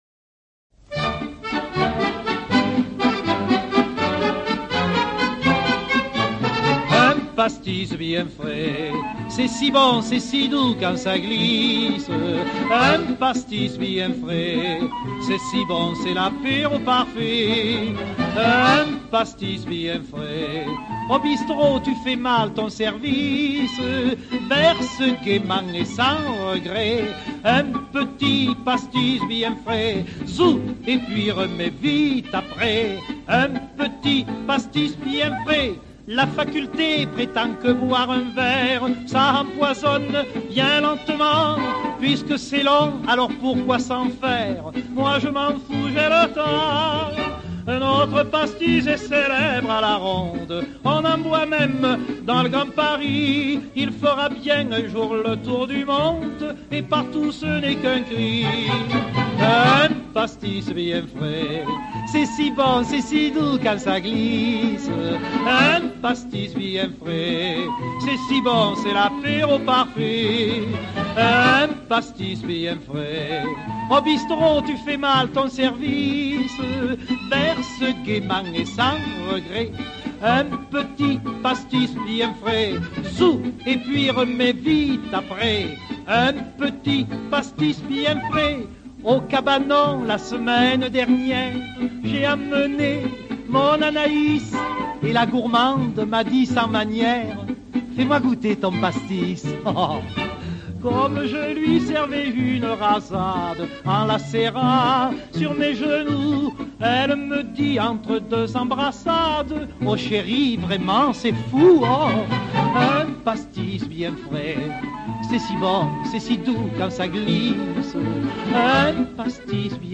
enregistrée en 1947
répertoire des opérettes marseillaises